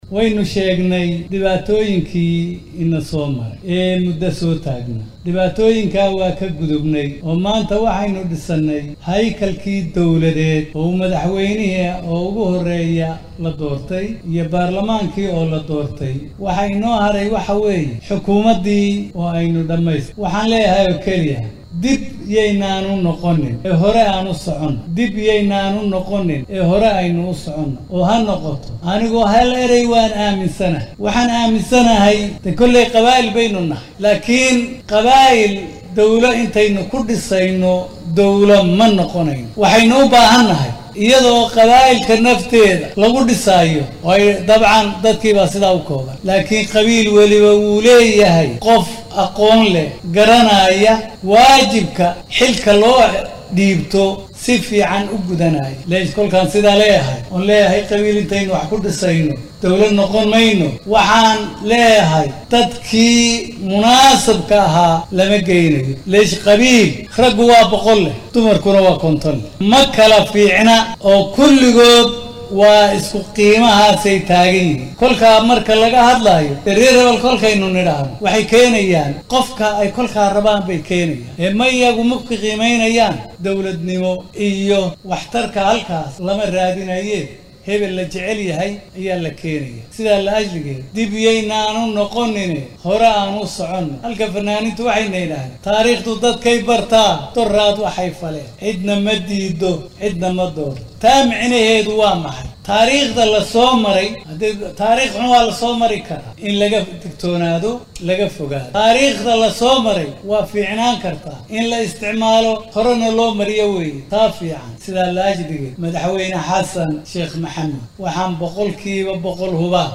Guddoomiyaha aqalka sare ee Soomaaliya Cabdi Xaashi Cabdullaahi oo xalay ka qayb galay munaasabad lagu bogaadinayay guddiyadii ka soo shaqeeyay doorashadii